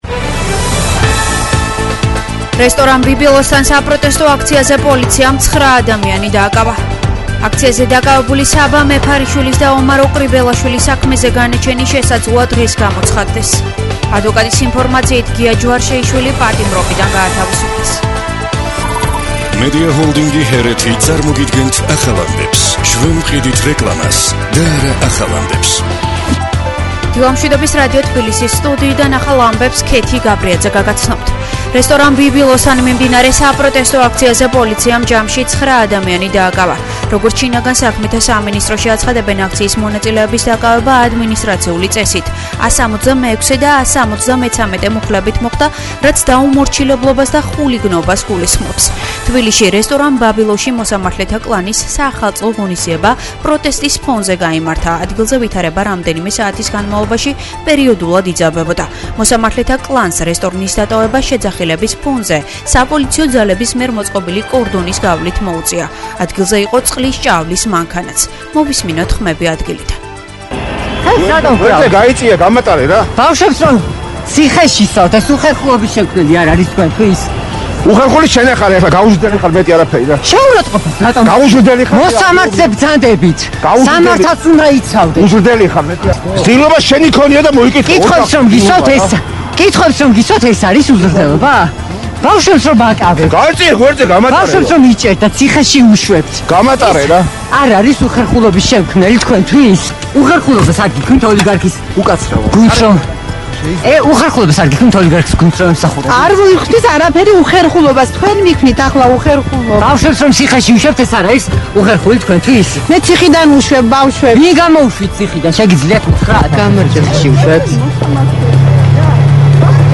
ახალი ამბები 09:00 საათზე